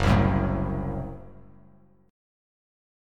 F#dim chord